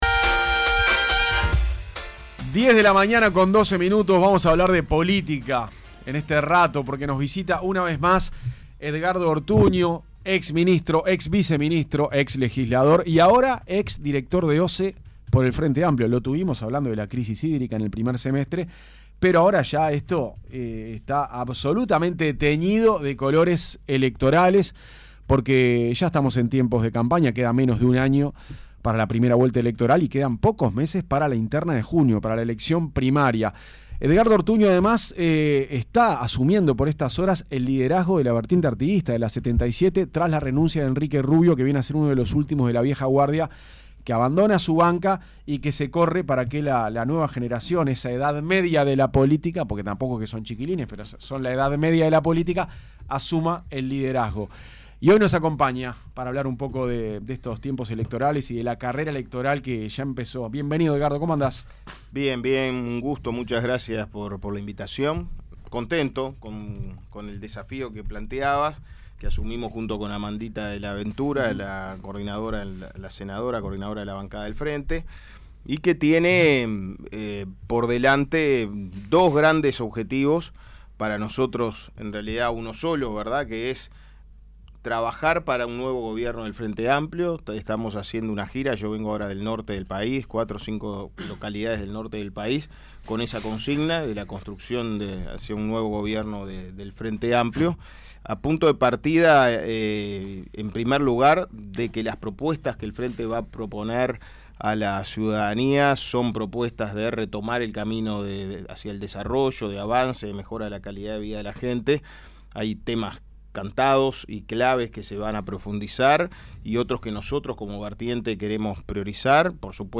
Entrevista-a-Edgardo-Ortuno.mp3